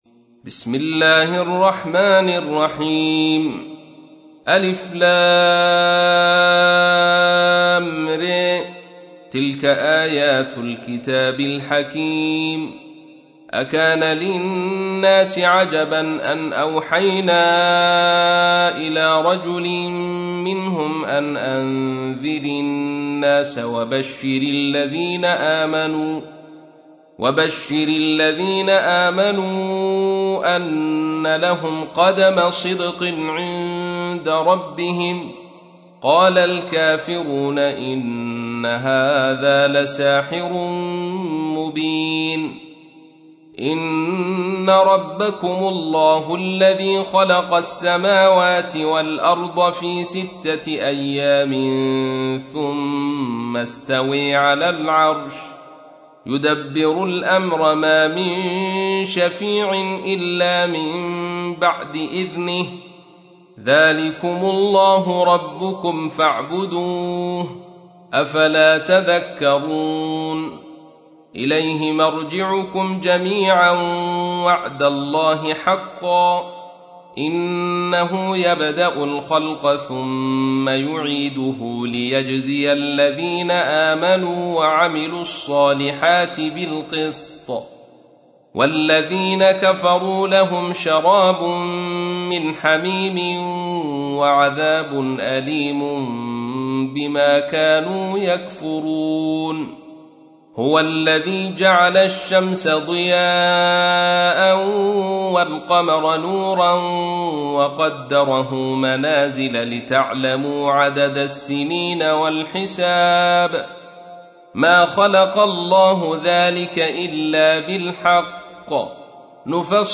تحميل : 10. سورة يونس / القارئ عبد الرشيد صوفي / القرآن الكريم / موقع يا حسين